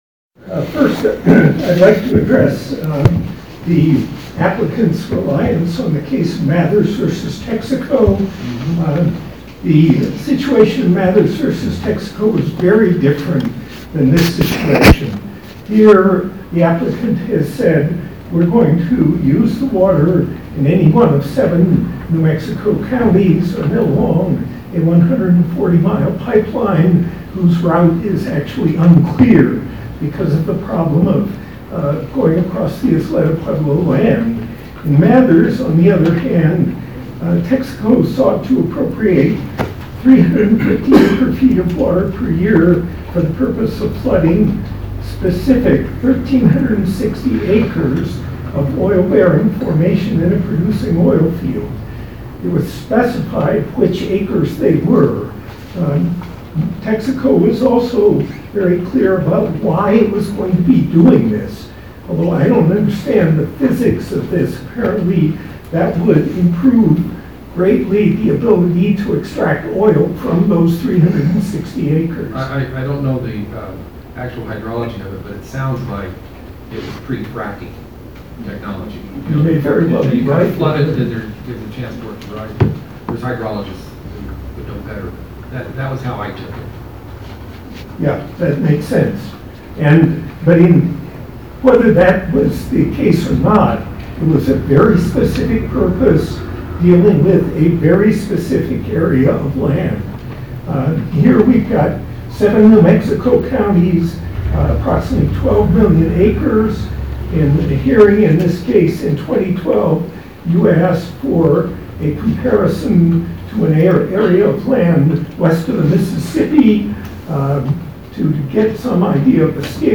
APR HEARING JULY 26, 2019
Standing room only once the hearing started.
Presiding over a packed courtroom, Judge Matthew Reynolds heard arguments on June 26 relating to the Augustin Plains Ranch LLC’s (APR) application to mine water in the Plains of San Augustin basin.